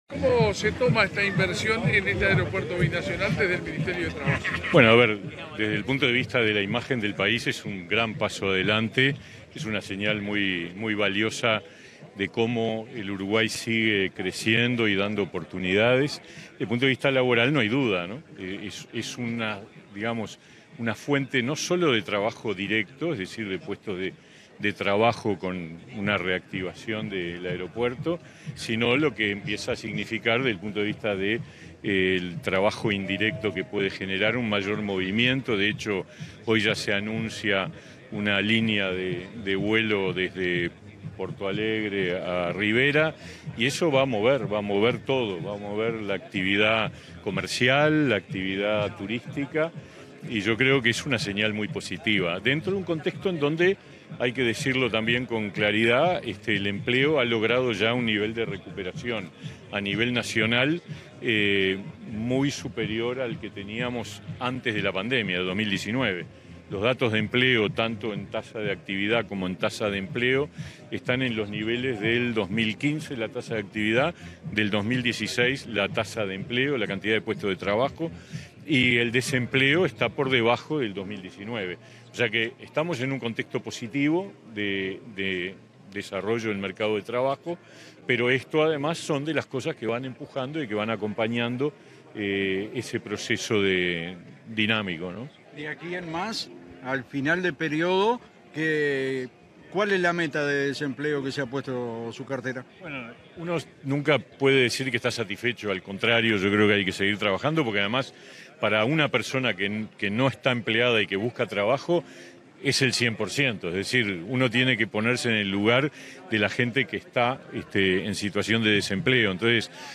Declaraciones a la prensa del ministro de Trabajo, Pablo Mieres
Declaraciones a la prensa del ministro de Trabajo, Pablo Mieres 12/12/2023 Compartir Facebook Twitter Copiar enlace WhatsApp LinkedIn En el marco de la inauguración del aeropuerto binacional de Rivera, este 11 de diciembre, el ministro de Trabajo y Seguridad Social (MTSS), Pablo Mieres, dialogó con la prensa.